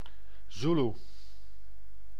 Ääntäminen
IPA : /ˈsævɪdʒ/